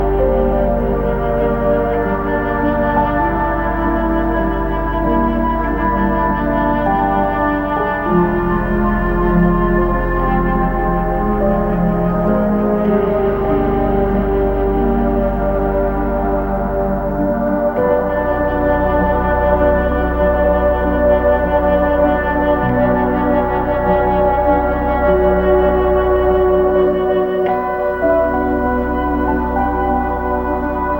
Musique audio
chant